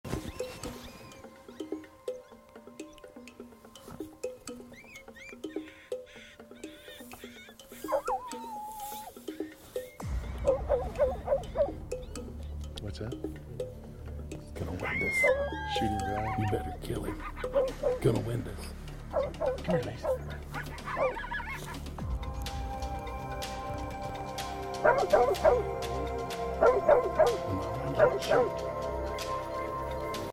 Back Talking Coyote Called In Sound Effects Free Download